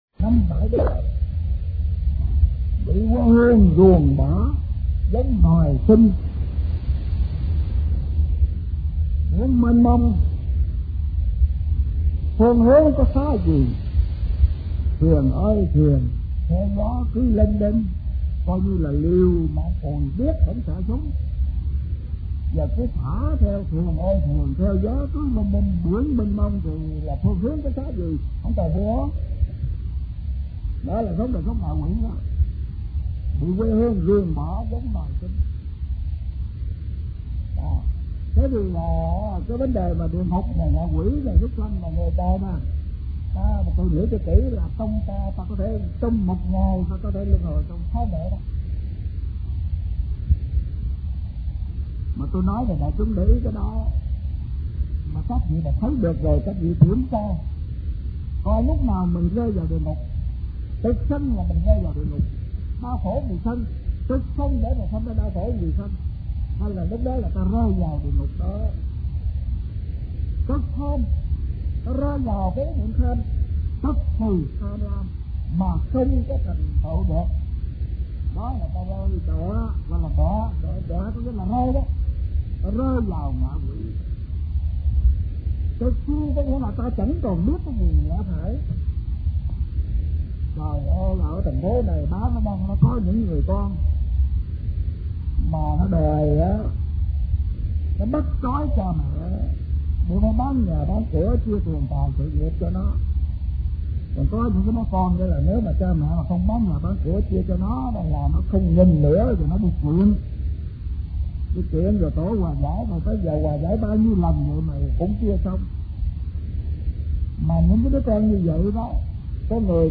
Kinh Giảng Bồi Dưỡng Phật Pháp